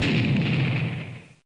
bomb.opus